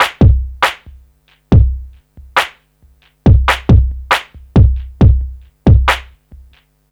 C E.BEAT 2-R.wav